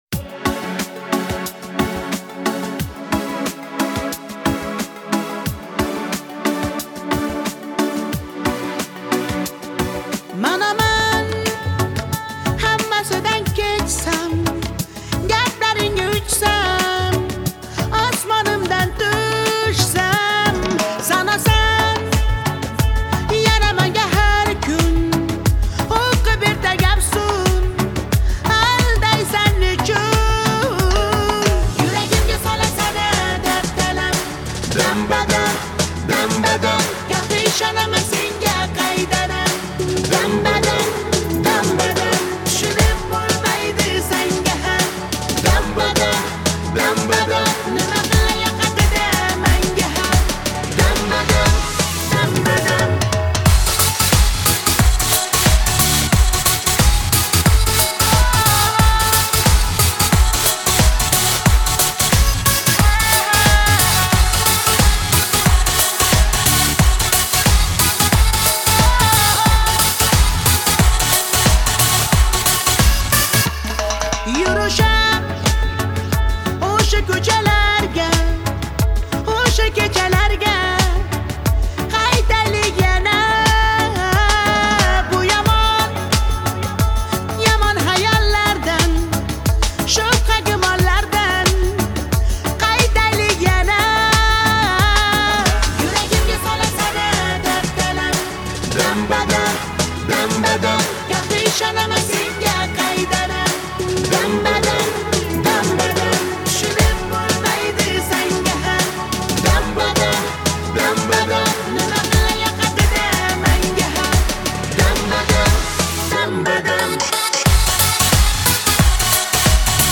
это яркая и энергичная песня в жанре узбекской поп-музыки